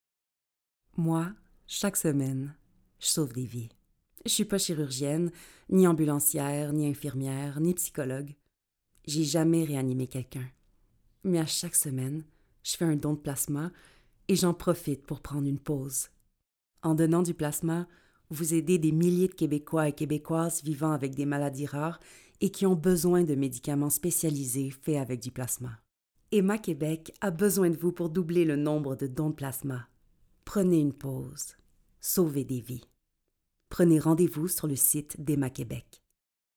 Démo de voix
Publicité Héma-Qc - Démo Fictif